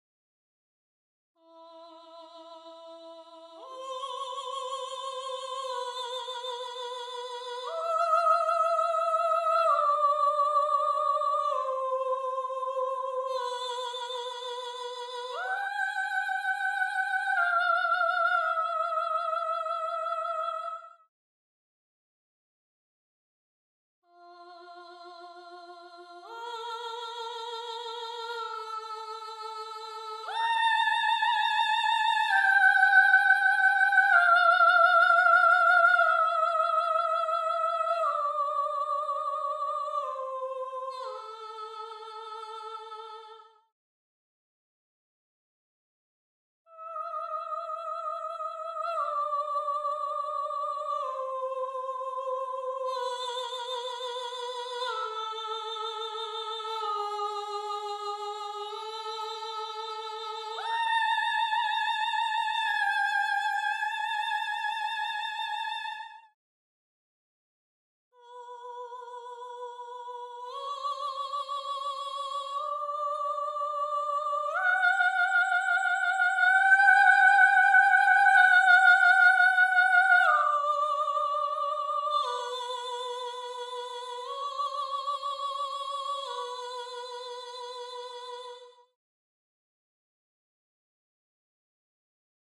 1. SOPRANO (Soprano/Soprano)
gallon-v3s4-20-Soprano_0.mp3